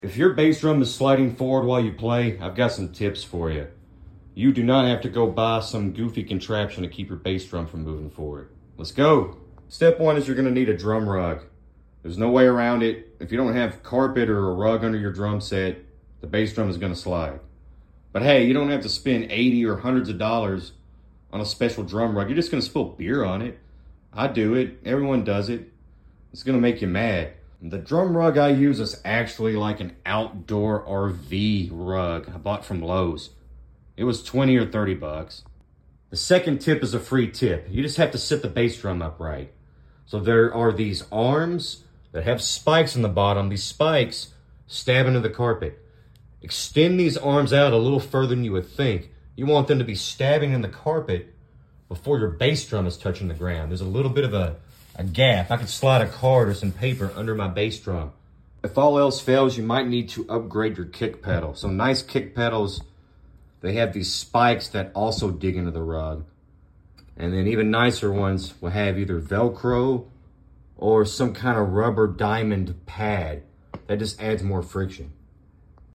Bass Drum Sliding Forward? Sound Effects Free Download